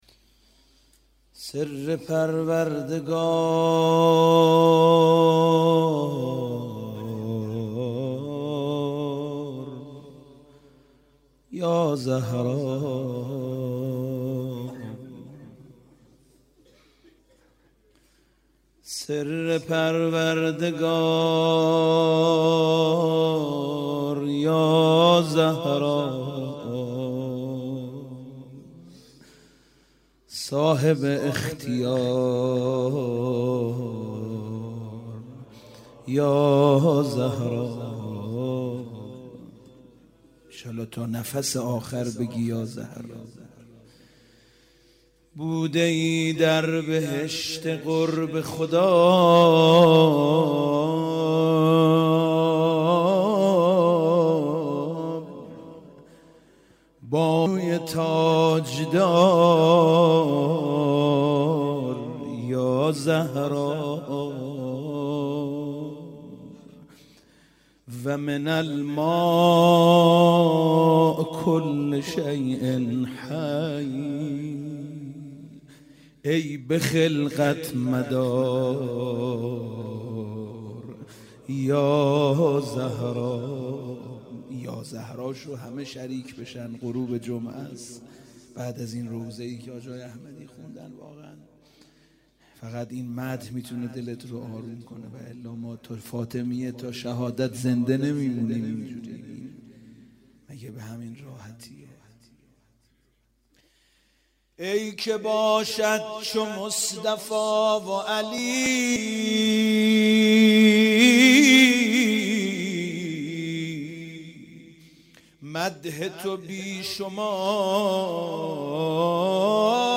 روضه - سر پروردگار یا زهرا